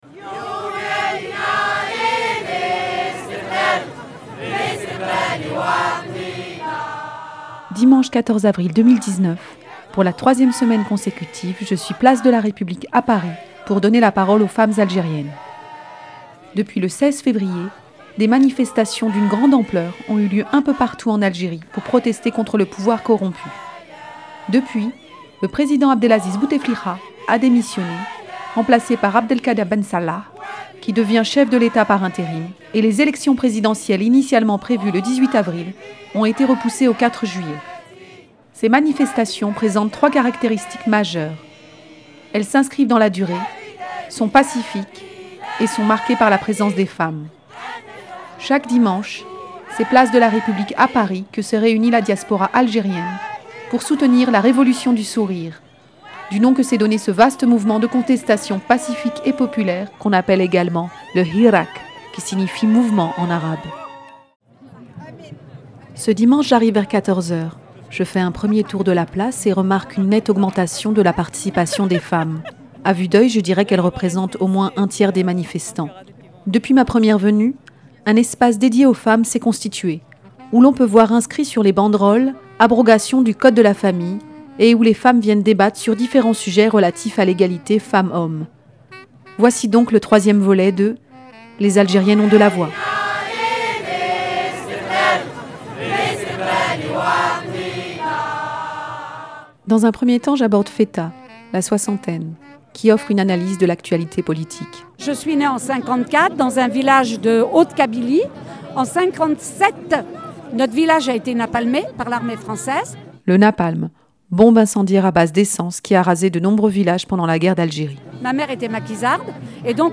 Chaque dimanche, c’est sur la place de la république à Paris que se réunit la diaspora algérienne pour soutenir la "Révolution du sourire" du nom que s’est donné ce vaste mouvement de contestation pacifique et populaire.
Des jeunes et des moins jeunes s’expriment tour à tour sur la situation actuelle et la place des femmes dans l’Algérie de demain. On parle également de la présence des femmes dans les luttes algériennes, du code de la famille, du poids des traditions, des enjeux de la mixité et de la jeunesse.